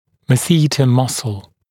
[məˈsiːtə ‘mʌsl][мэˈси:тэ ‘масл]жевательная мышца